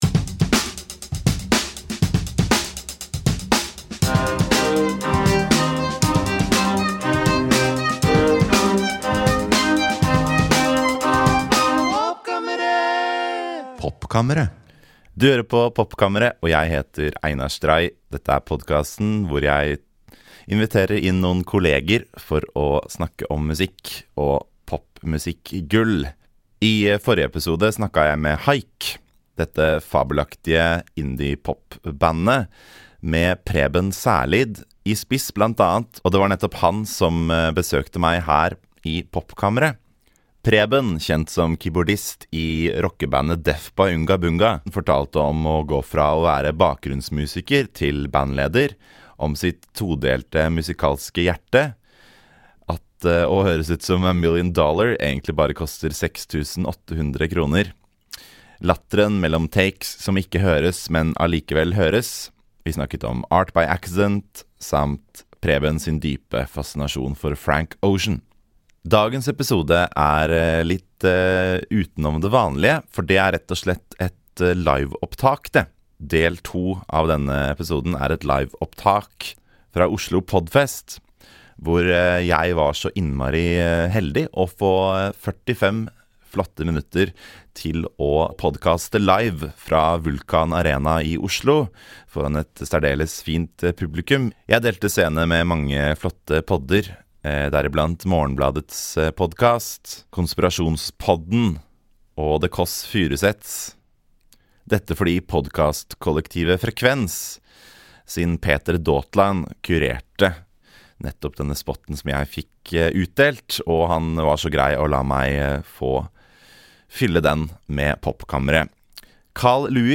Dagens episode er et liveopptak fra Oslo Podfest.